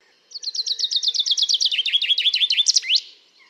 Большинство песен зяблика устроено так: вначале идет одна или несколько разных трелей (серий одинаковых нот), а в конце звучит заключительная громкая фраза — «росчерк».
Представьте себя Питером Марлером, послушайте записи песен шести зябликов и подберите для каждой из них трели и «росчерк» из предложенных схем.
chaffinch3.mp3